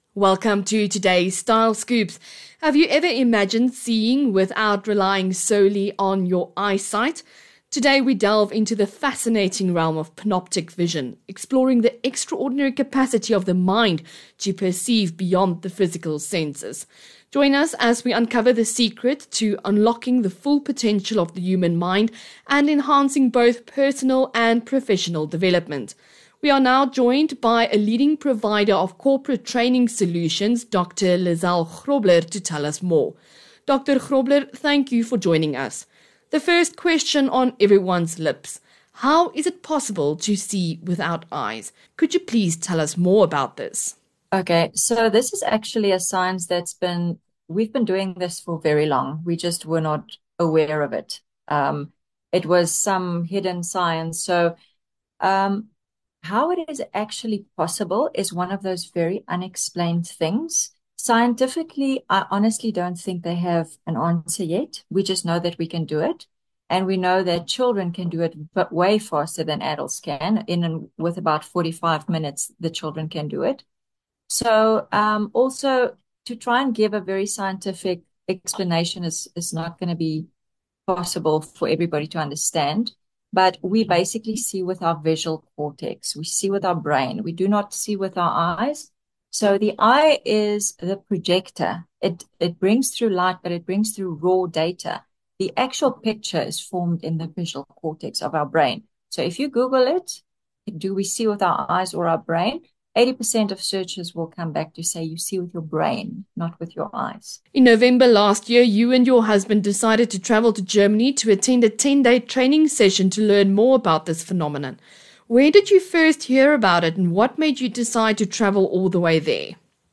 28 May INTERVIEW